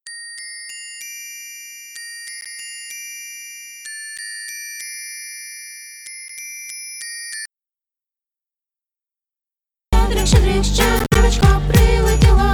Приложил пример запилов и щелчков, вот например колокольчики - это в начале проекта, интро песни, там играет один трек колокольчиков в аудио, и все равно умудряется запиливаться.